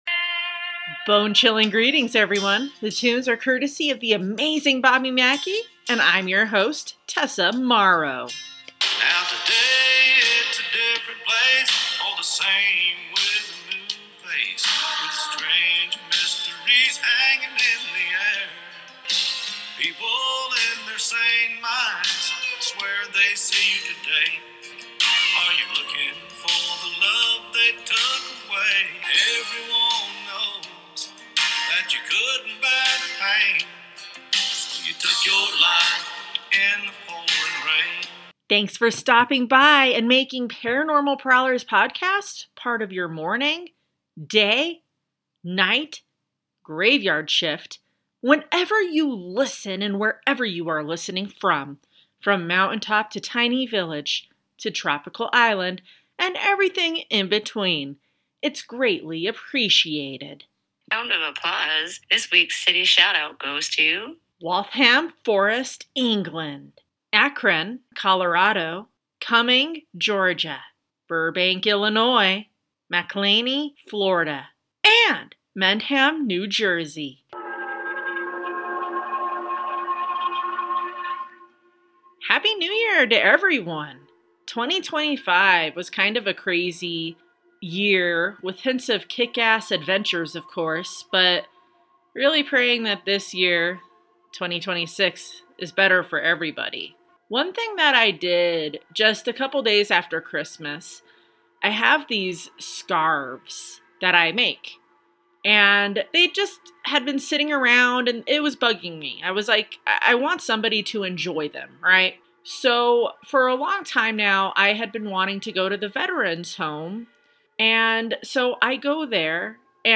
VOICEOVERS: